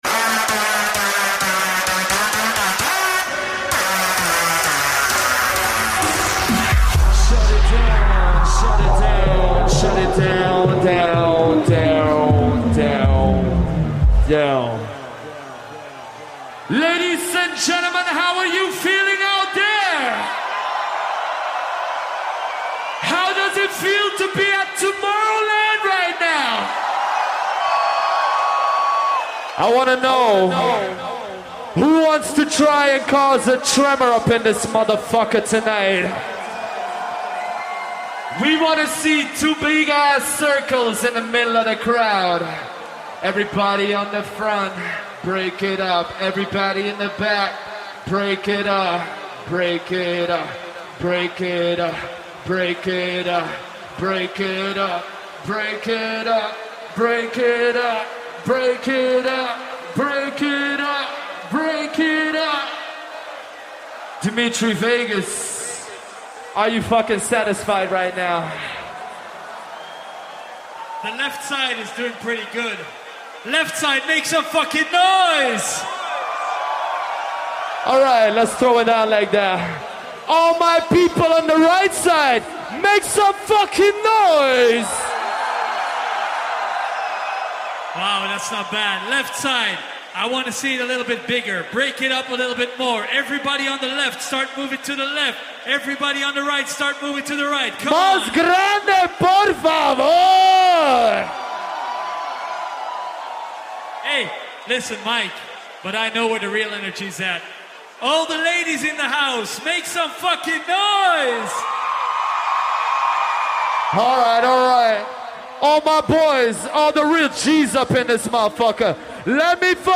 Also find other EDM
Liveset/DJ mix